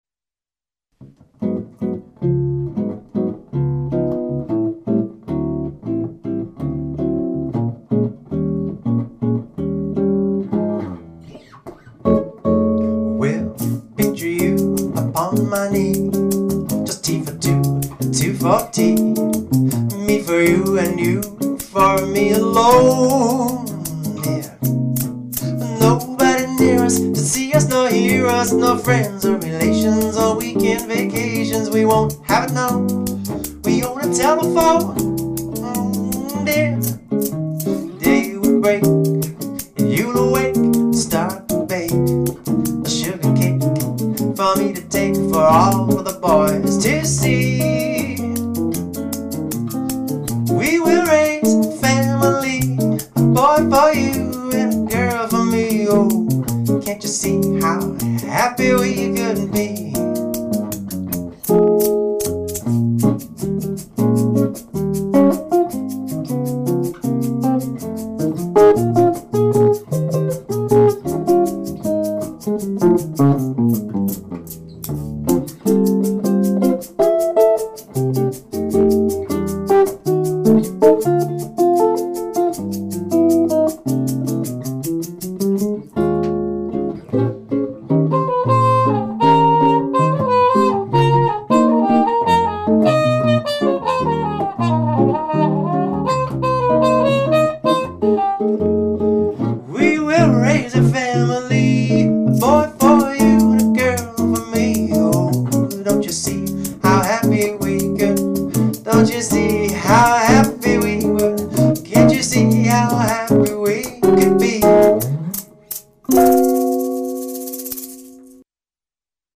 swinging singing (+trumpet)